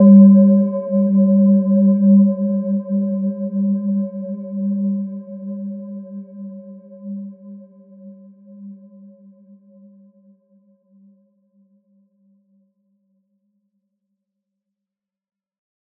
Gentle-Metallic-4-G3-f.wav